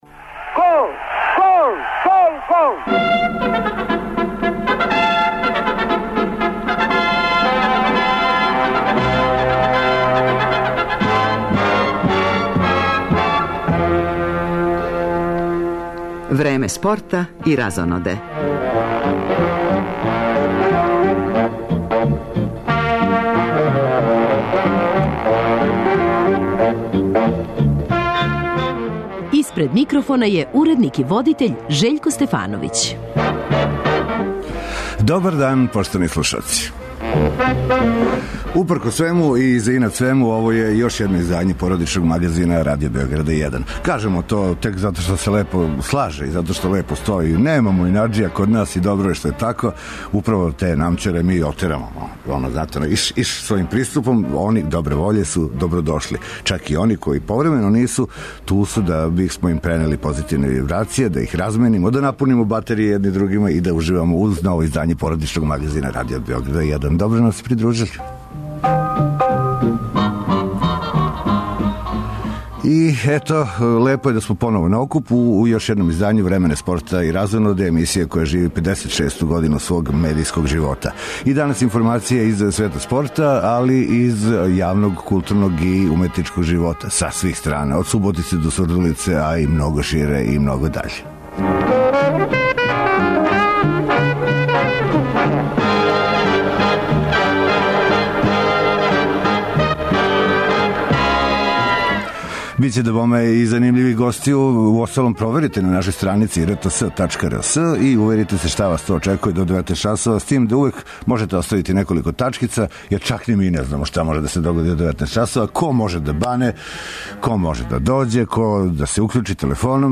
У жељи да врата ове емисије отворимо и млађим музичким снагама, данас ћемо упознати шест састава, чути њихове биографије и по једну нумеру - у овом делу програма, представиће нам се Бранка и Хијавата, група Патриа, Роланд бенд, састав Странци, Стендбај бенд и група Варварин.